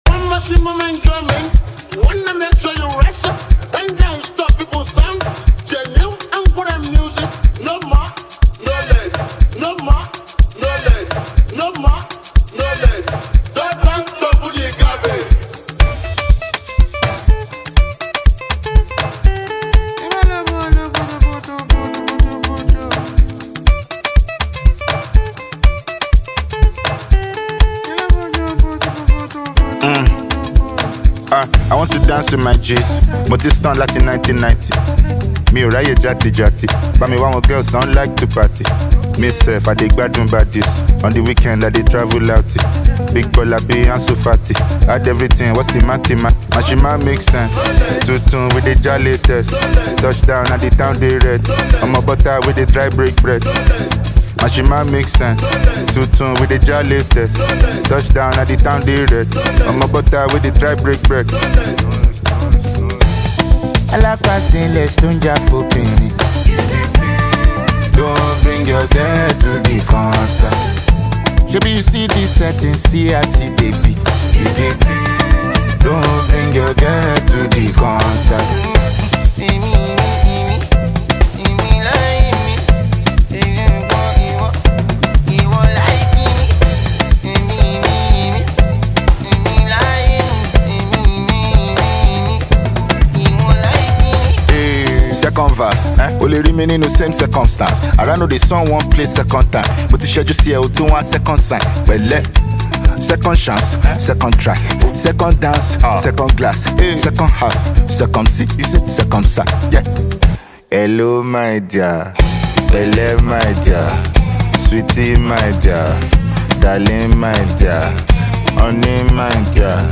Nigerian rap